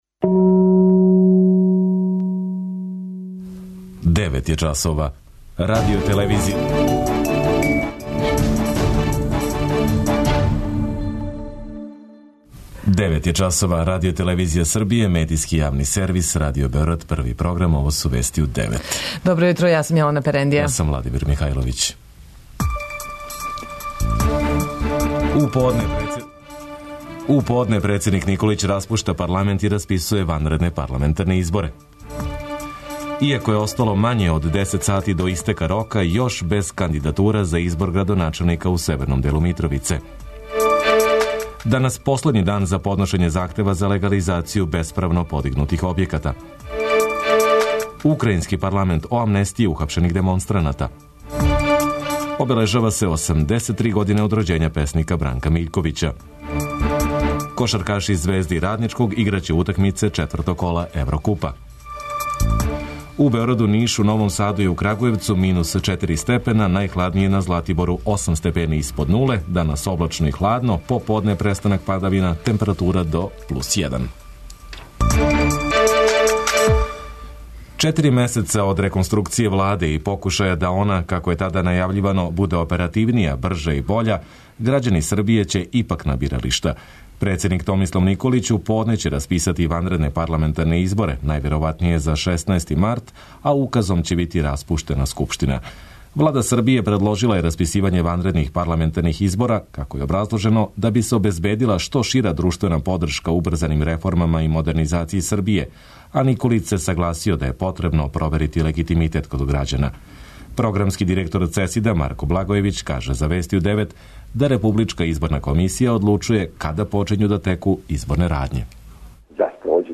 преузми : 9.99 MB Вести у 9 Autor: разни аутори Преглед најважнијиx информација из земље из света.